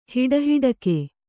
♪ hiḍahiḍake